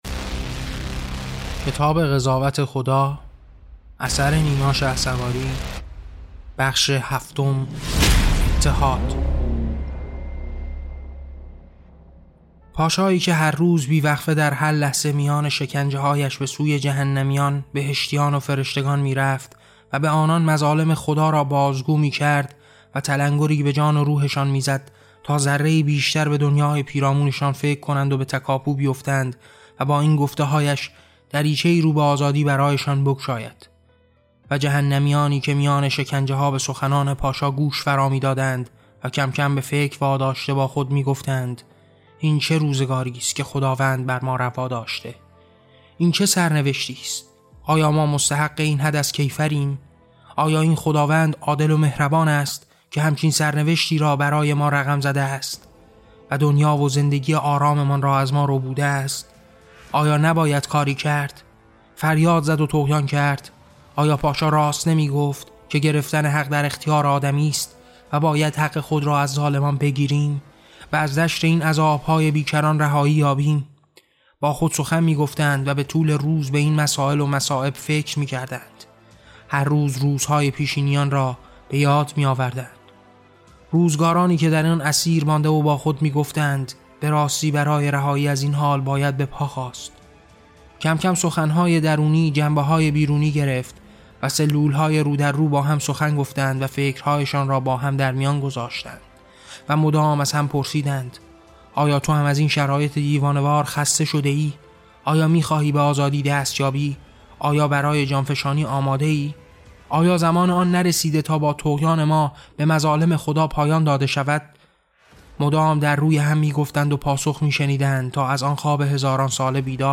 کتاب صوتی قضاوت خدا؛ بخش هفتم: عصیان پاشا در میان رنج و فراخوان بیداری